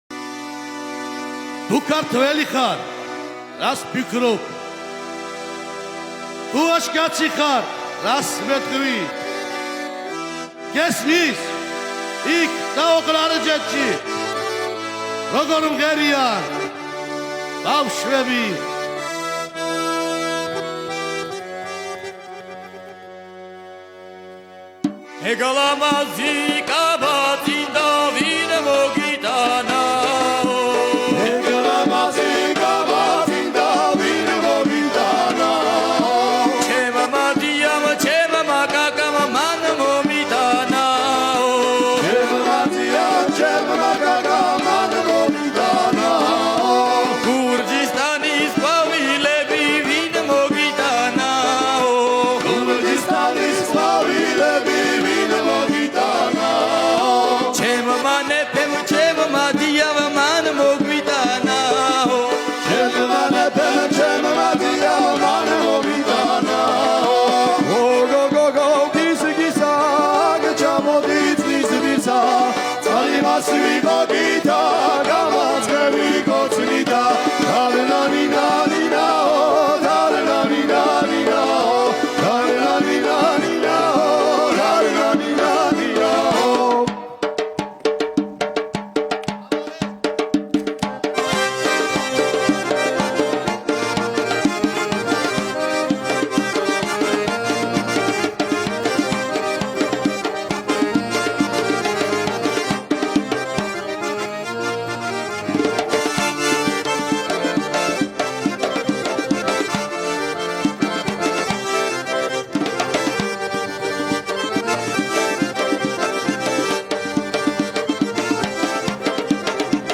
Georgian music